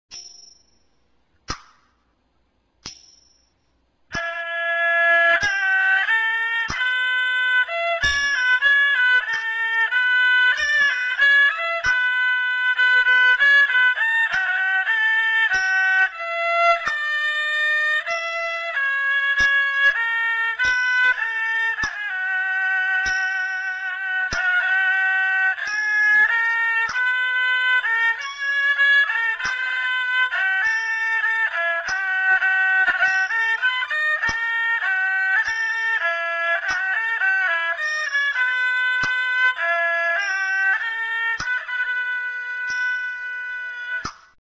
Khryang Sii : bowed instruments
Saw Duang
The saw duang is similar to the Chinese stringed instrument called hu chin, and may have been copied from this Chinese model.